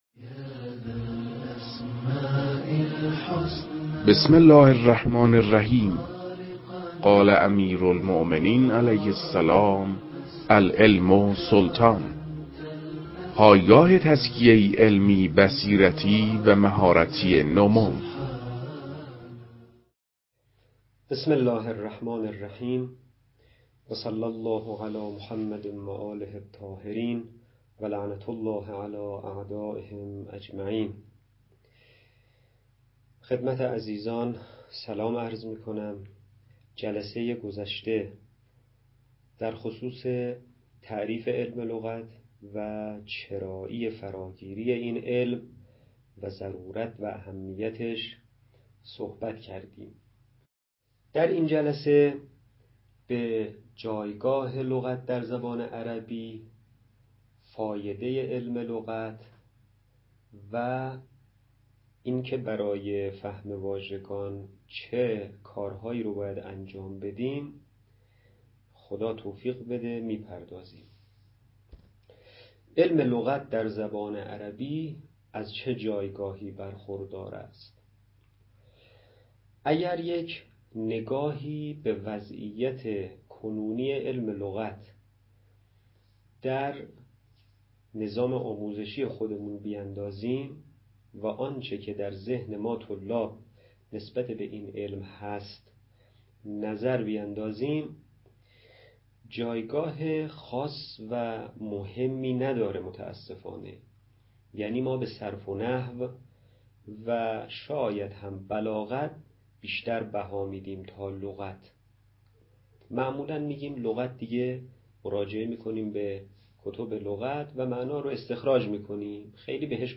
در این بخش، کتاب «درآمدی بر لغت‌شناسی» که اولین کتاب در مرحلۀ آشنایی با علم لغت است، به صورت ترتیب مباحث کتاب، تدریس می‌شود.
در تدریس این کتاب- با توجه به سطح آشنایی کتاب- سعی شده است، مطالب به صورت روان و در حد آشنایی ارائه شود.